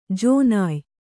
♪ jōnāy